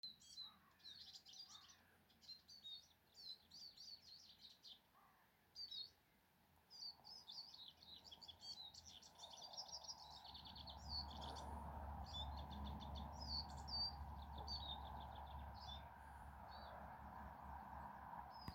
Birds -> Finches ->
Siskin, Spinus spinus
NotesDzied